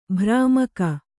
♪ bhrāmaka